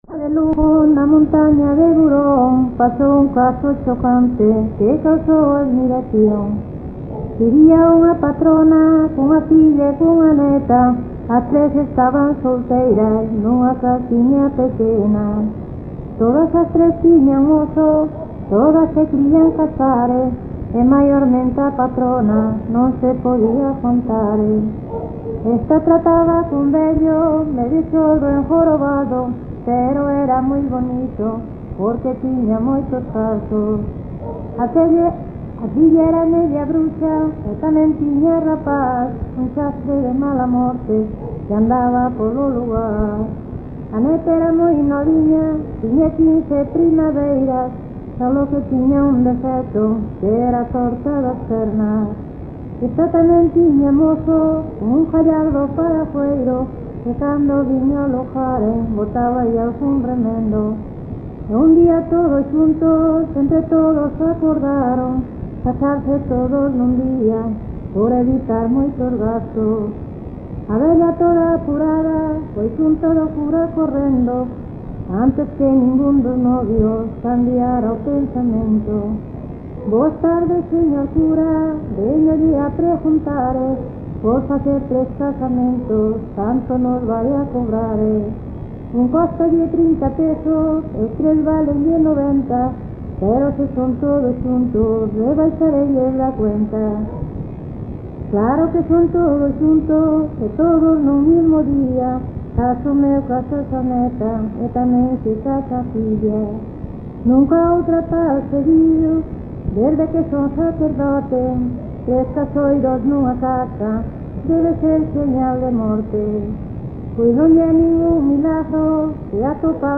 Concello: Mesía.
Áreas de coñecemento: LITERATURA E DITOS POPULARES > Cantos narrativos
Soporte orixinal: Casete
Instrumentación: Voz
Instrumentos: Voz feminina